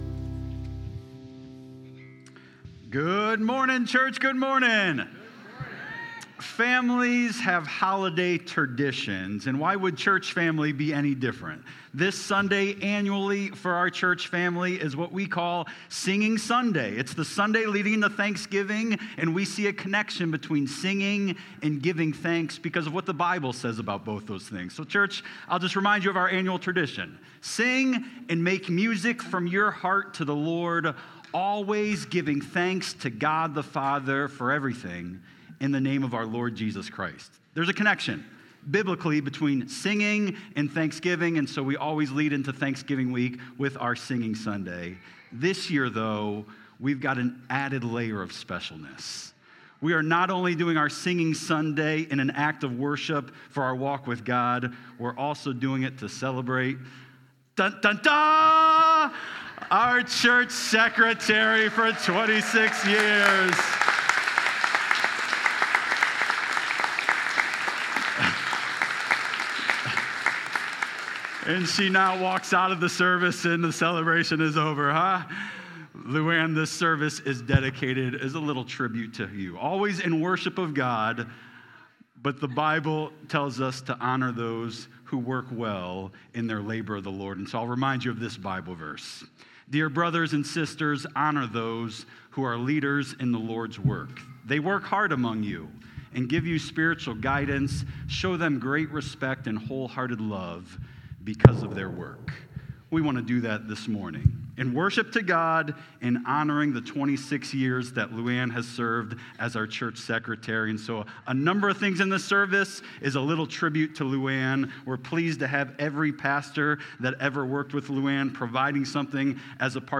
Current Sermon
Guest Speaker